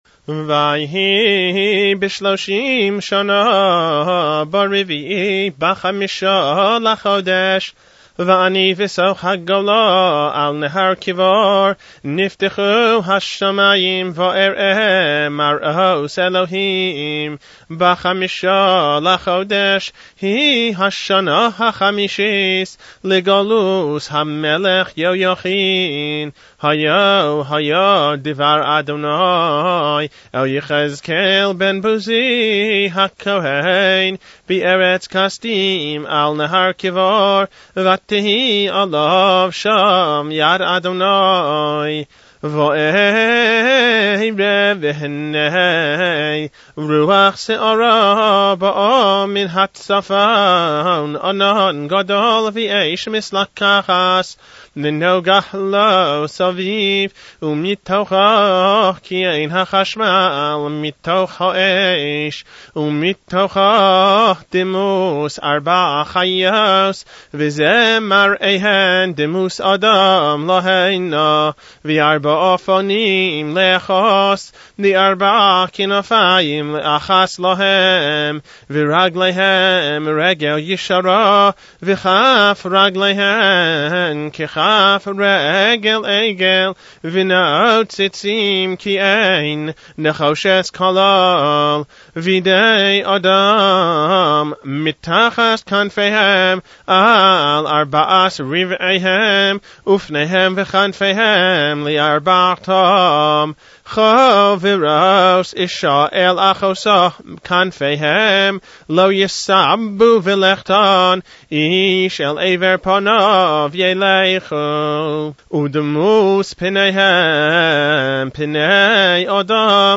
Leins Haftarah